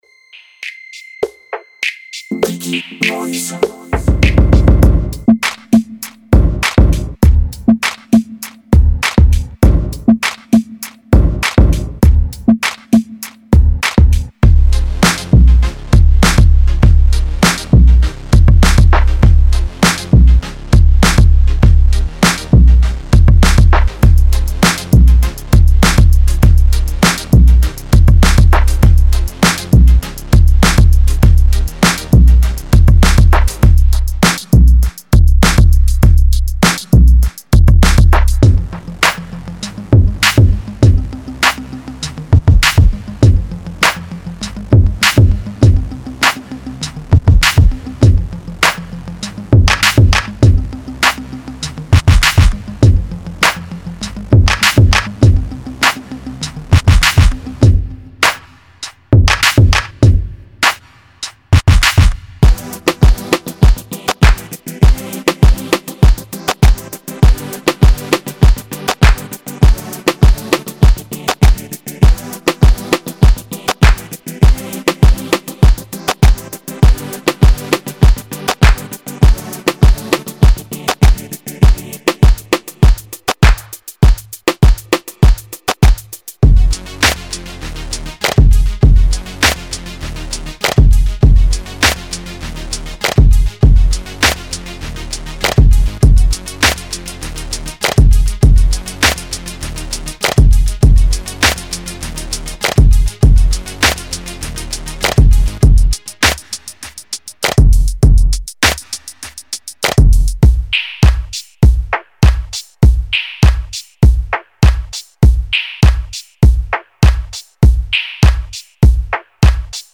250 golpes de batería con procesamiento HQ